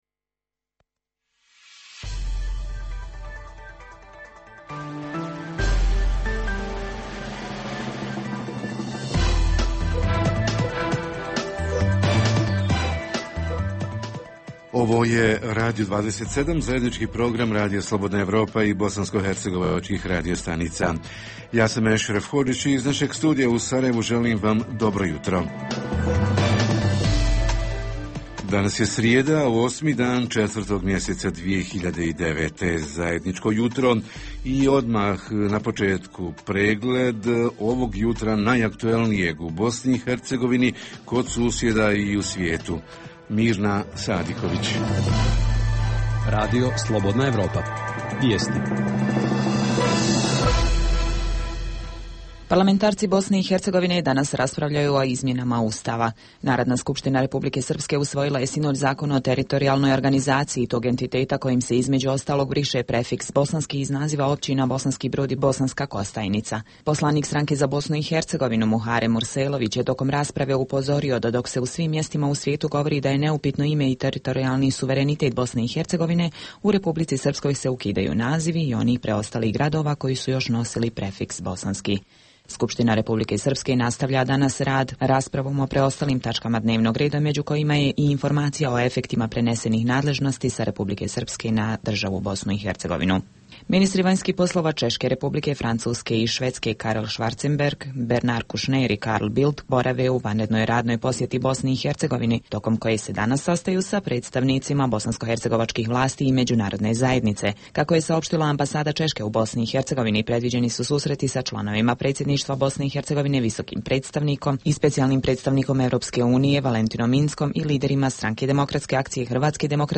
Jutarnji program za BiH koji se emituje uživo ima za temu početak građevinske sezone. Obzirom na ekonomsku krizu, pitamo ima li posla za građevinare? Reporteri iz cijele BiH javljaju o najaktuelnijim događajima u njihovim sredinama.